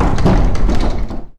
rattle2.wav